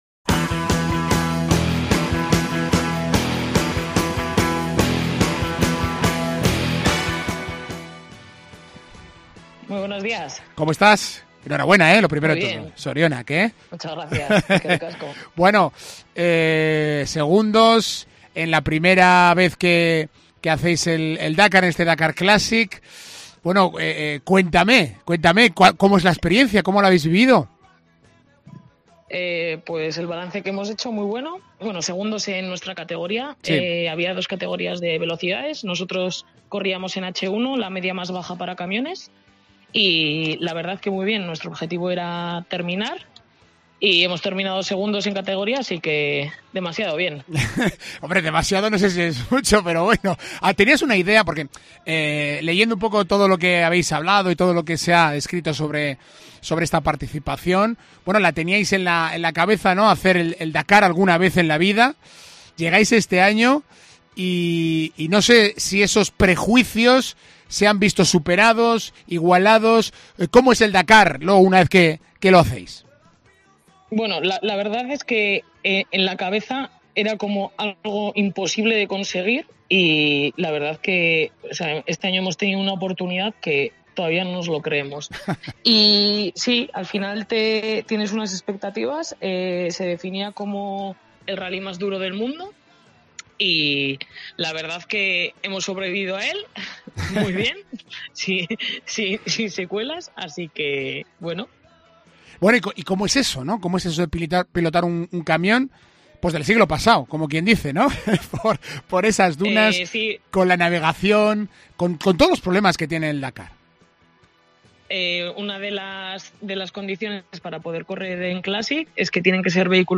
En Deportes Cope Vitoria hemos hablado sobre la experiencia de participar en el Dakar